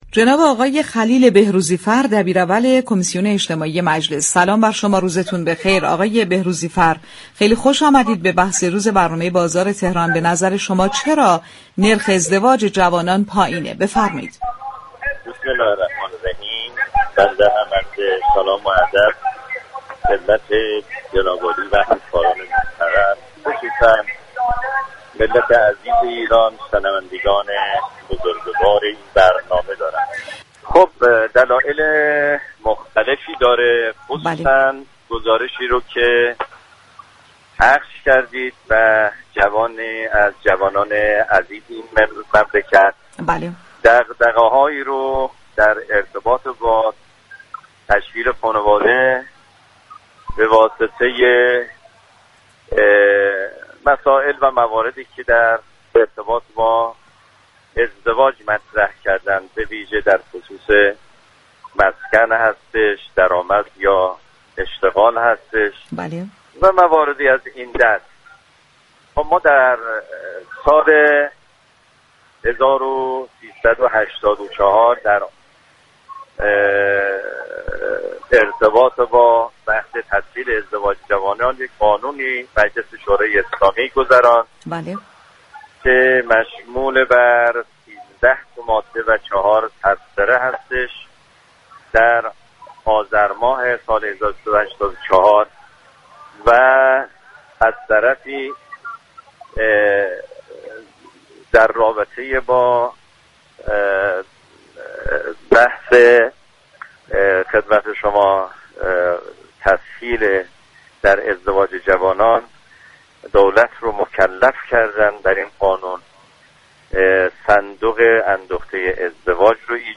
به گزارش پایگاه اطلاع رسانی رادیو تهران، خلیل بهروزی‌فر دبیر اول كمیسیون اجتماعی مجلس شورای اسلامی در گفتگو با بازار تهران رادیو تهران با تاكید بر اینكه كاهش ازدواج در ایران دلایل مختلفی دارد گفت: آذرماه سال 1384 در ارتباط با تسهیل ازدواج جوانان قانونی در مجلس تصویب شد كه شامل 13 ماده و 4 تبصره است.